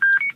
Test beep boops
xmit_off.ogg